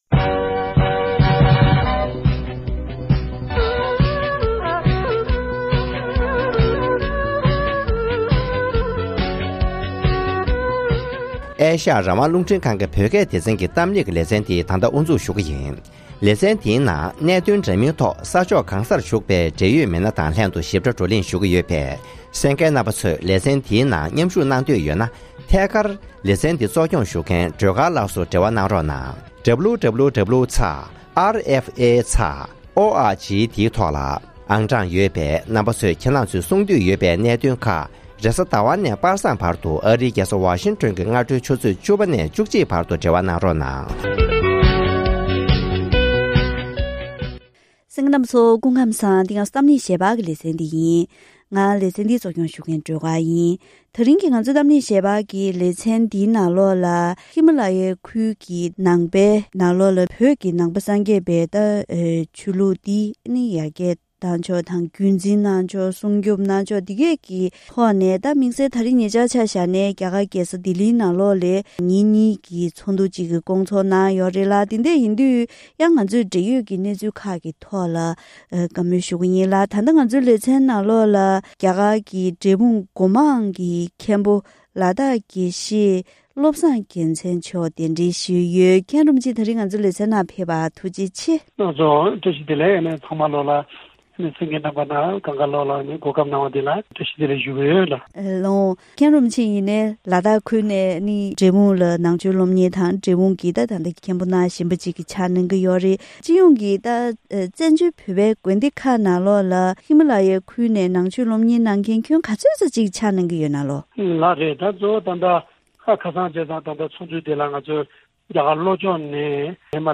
༄༅༎དེ་རིང་གི་གཏམ་གླེང་ཞལ་པར་ལེ་ཚན་ནང་རྒྱ་གར་གྱི་ཧི་མ་ལ་ཡའི་སྤྱི་ཚོགས་སུ་རང་ཉིད་ཀྱི་ངོ་བོ་དང་རིག་གཞུང་སྲུང་སྐྱོབ།